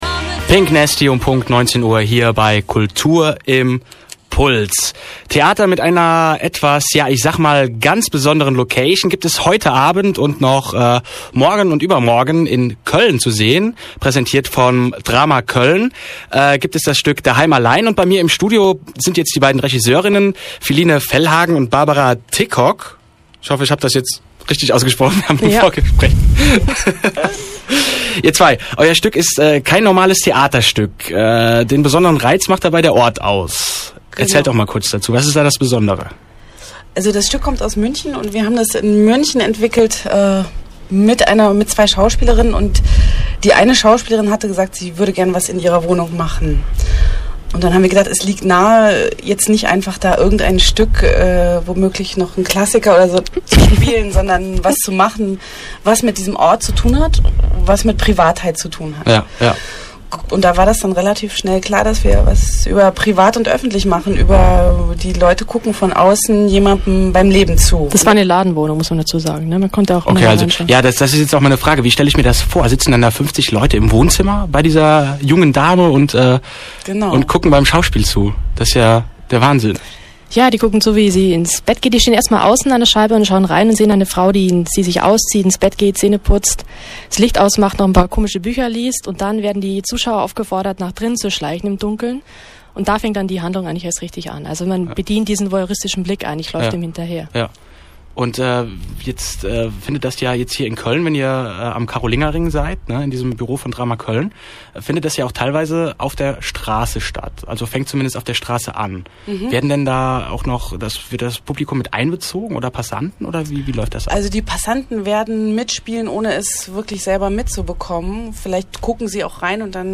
» Interview mit